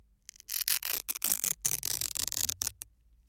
剥皮04
描述：被撕开的维可牢尼龙搭扣的混合物。快速。
标签： 翻录 撕裂 魔术贴 嘲笑 翻录 裂纹 剥离 撕裂 缫丝 剥离 切割
声道立体声